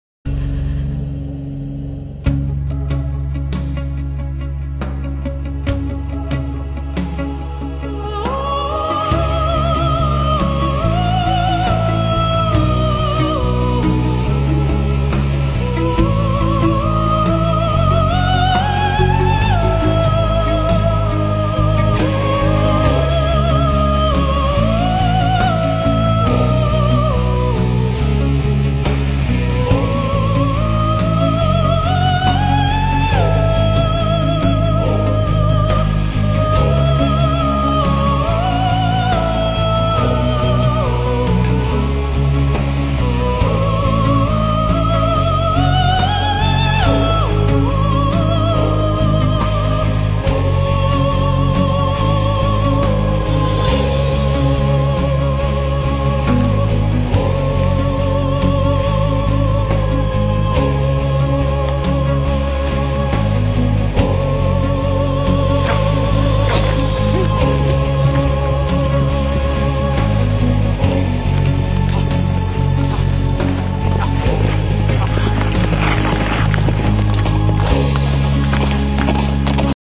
ببخشید که کیفیت پایین هس مقداری به بزرگی خودتون ببخشید